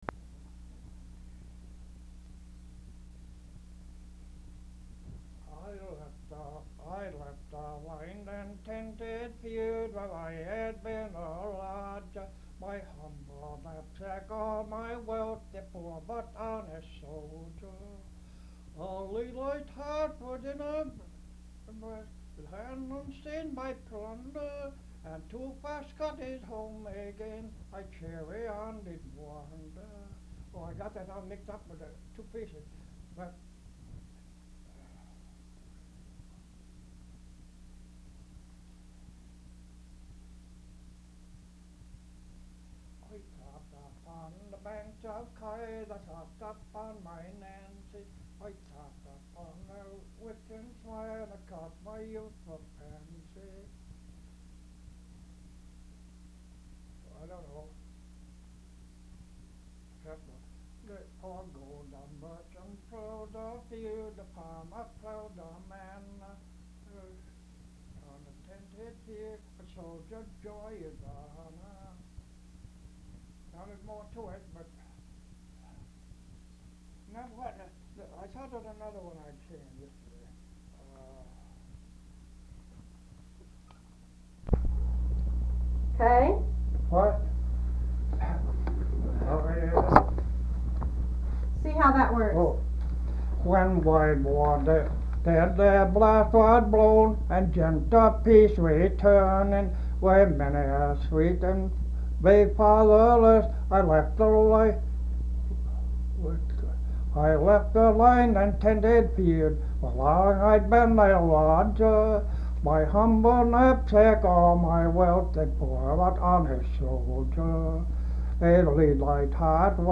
Folk songs, English--Vermont (LCSH)
sound tape reel (analog)
Location Marlboro, Vermont